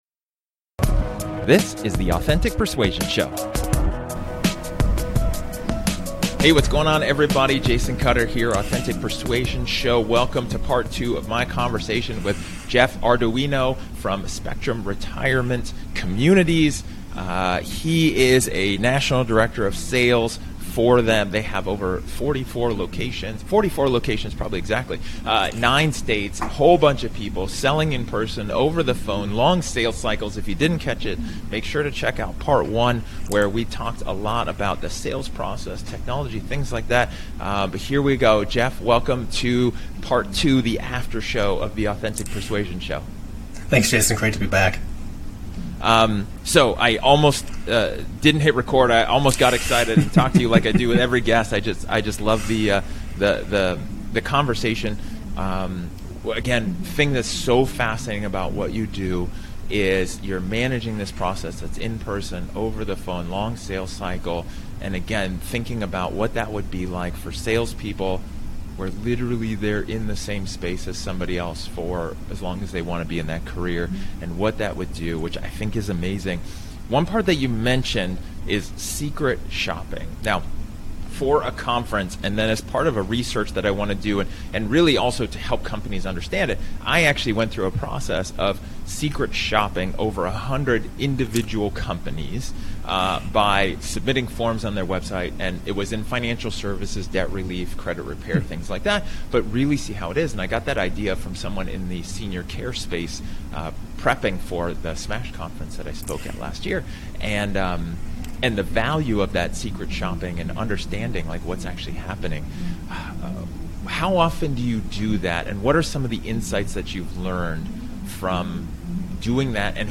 This is a casual conversation, off the cuff, and unscripted.